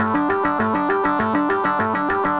Index of /musicradar/8-bit-bonanza-samples/FM Arp Loops
CS_FMArp A_100-A.wav